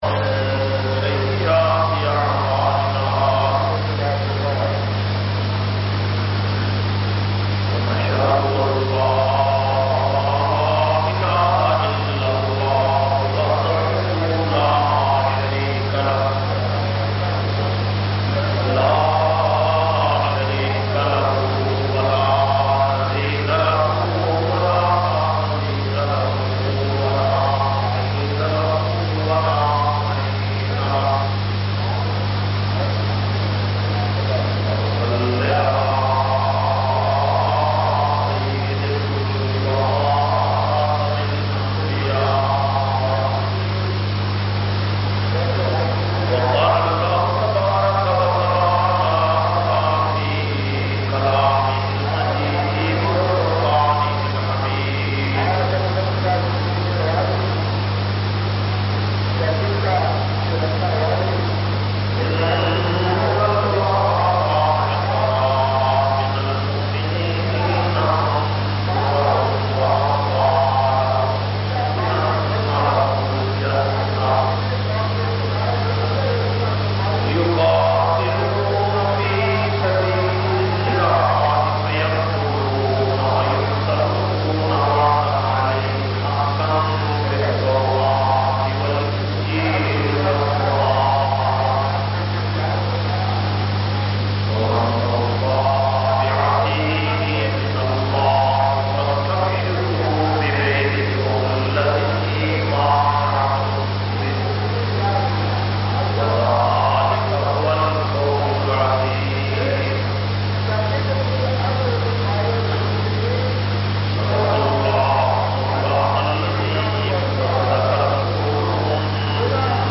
528- Shan e Sahaba r.a Jumma khutba Jamia Masjid Muhammadia Samandri Faisalabad.mp3